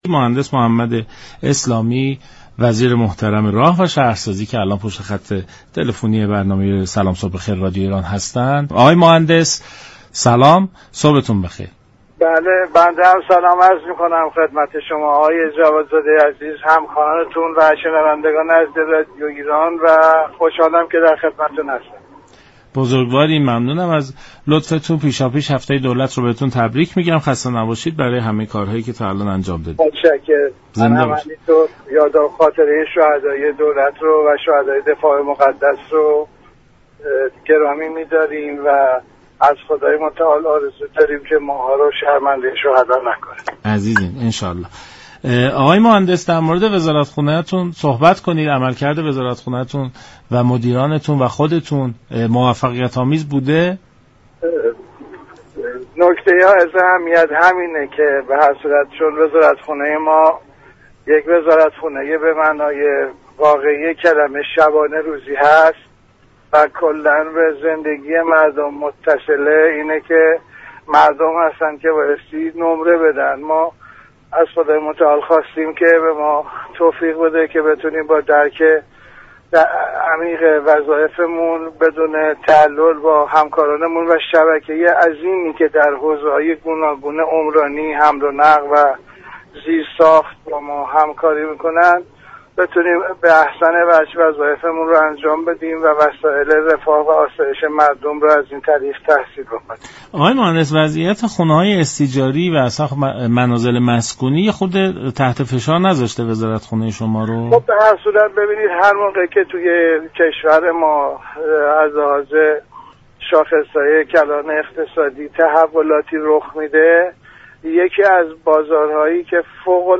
به گزارش شبكه رادیویی ایران، محمد اسلامی وزیر راه و شهرسازی در برنامه «سلام صبح بخیر» رادیو ایران ضمن گرامیداشت هفته دولت، به عملكرد وزارتخانه راه و شهرسازی پرداخت و گفت: این وزارتخانه به شكل شبانه روزی تلاش می كند وظائف خود را در حوزه عمرانی، حمل و نقل و زیرساخت انجام دهد و آسایش و رفاه را برای مردم ایجاد كند.